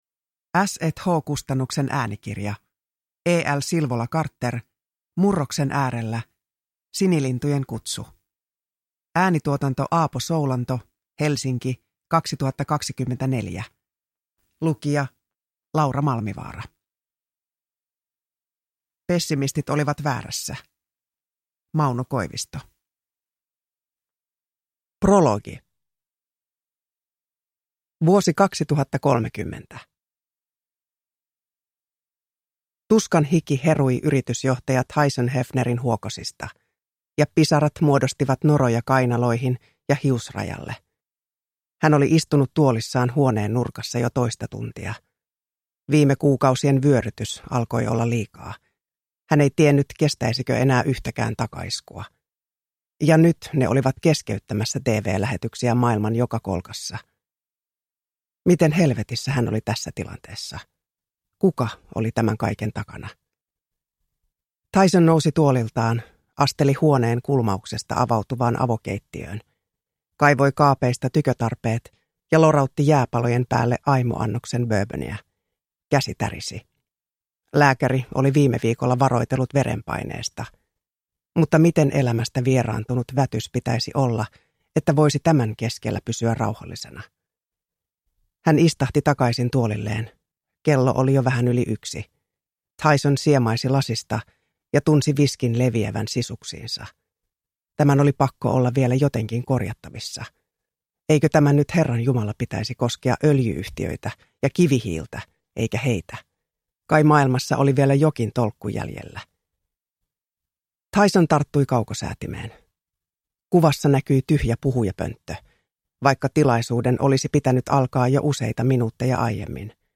Uppläsare: Laura Malmivaara
Ljudbok